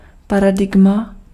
Ääntäminen
UK : IPA : /ˈpæɹ.ə.daɪm/